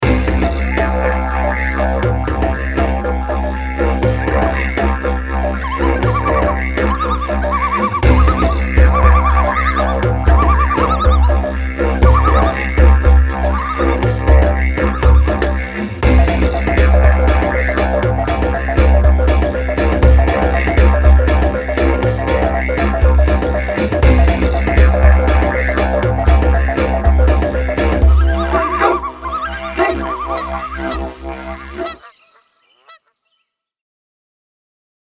Dschungelvertonung.mp3